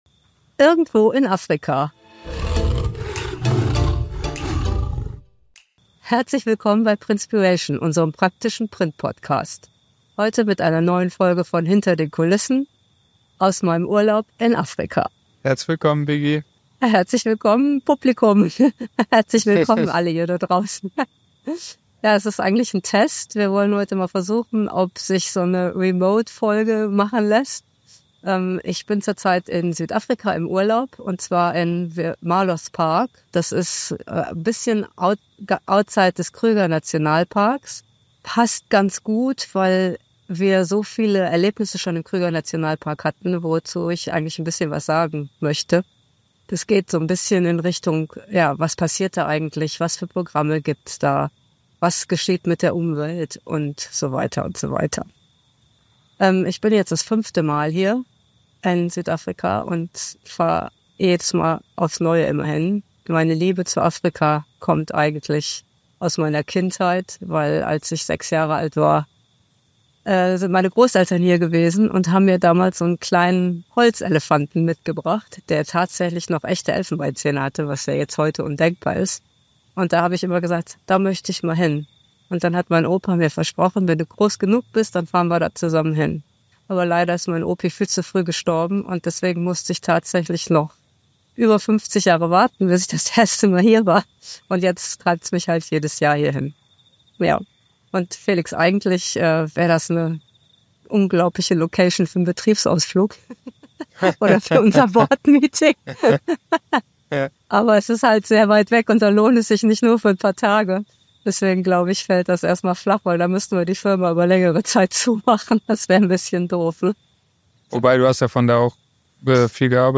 per Remote direkt aus Afrika